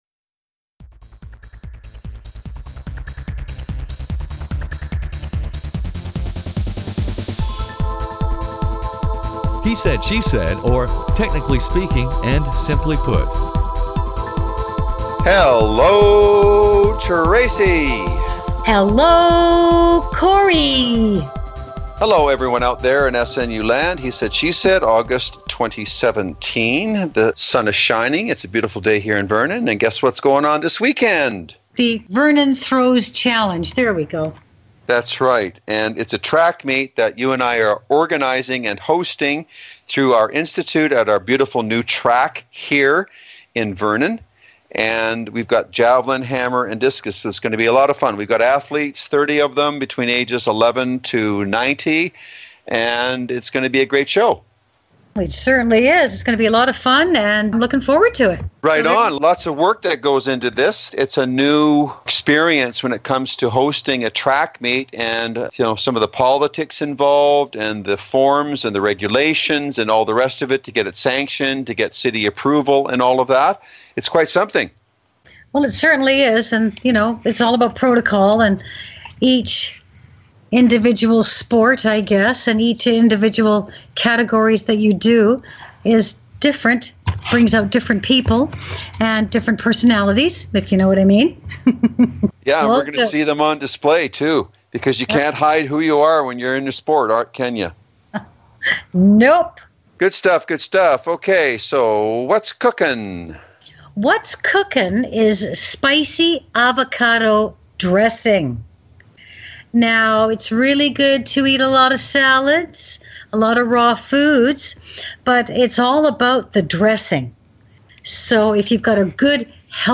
spontaneous and humorous dialog